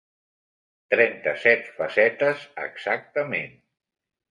Read more exactly Frequency A1 Pronounced as (IPA) [əɡˌzak.təˈmen] Etymology From exacte + -ment.